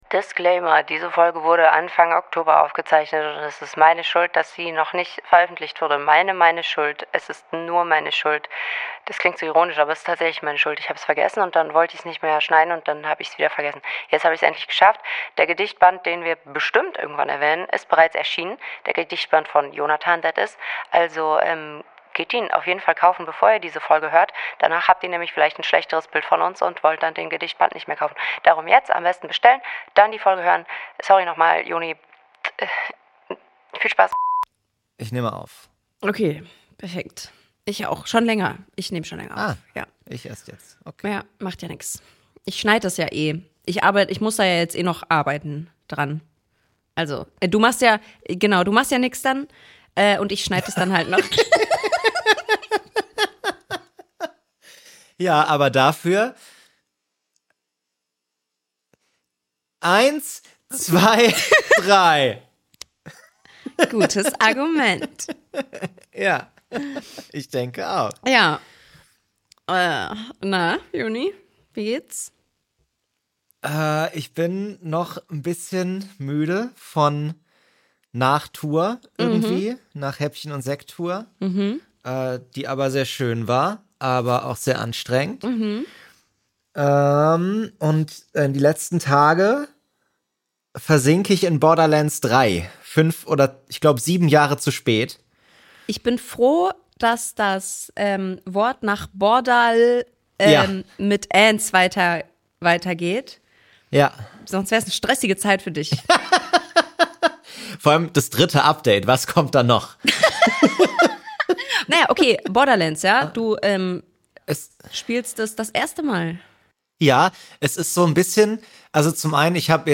Alle zwei Wochen treffen sich die beiden und stellen sich jeweils eine neue Idee für kleine Witze vor. Und dann denken sie zusammen weiter.
Comedy
Impro